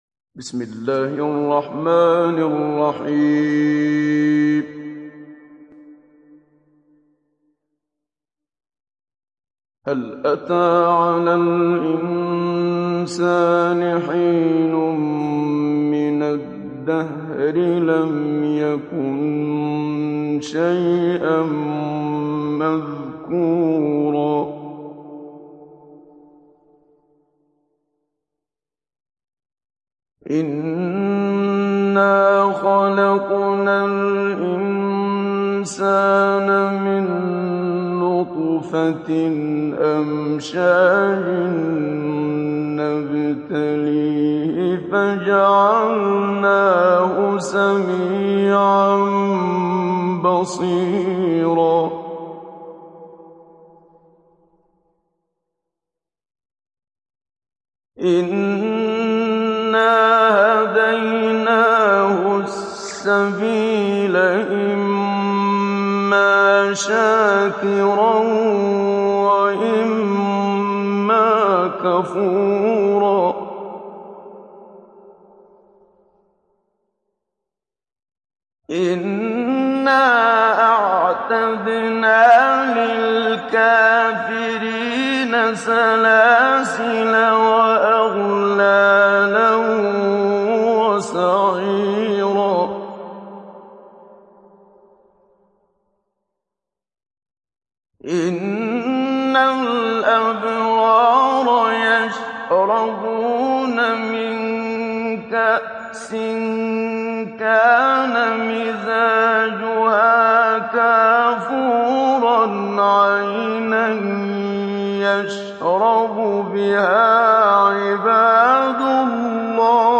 সূরা আল-ইনসান ডাউনলোড mp3 Muhammad Siddiq Minshawi Mujawwad উপন্যাস Hafs থেকে Asim, ডাউনলোড করুন এবং কুরআন শুনুন mp3 সম্পূর্ণ সরাসরি লিঙ্ক
ডাউনলোড সূরা আল-ইনসান Muhammad Siddiq Minshawi Mujawwad